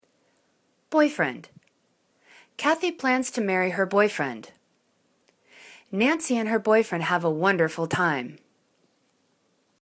boy.friend    /'boifrend/    n